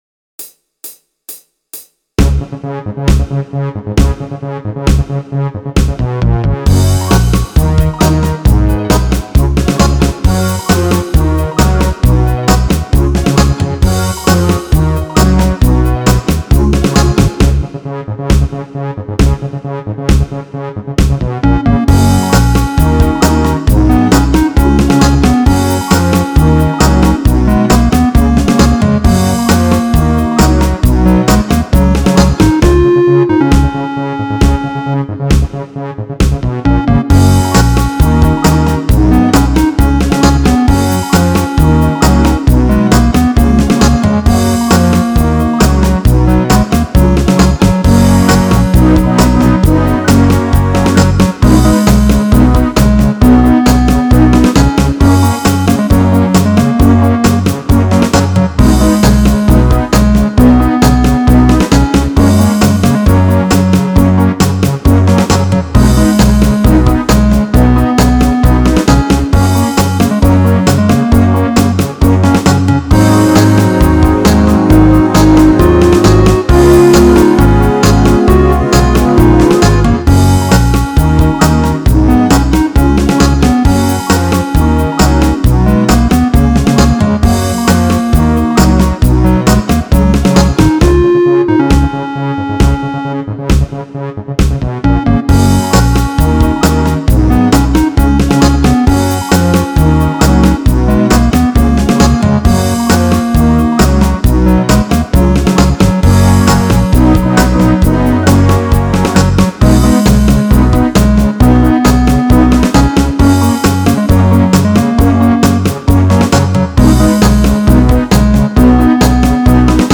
Keep the trombones and mute the backup vocal track.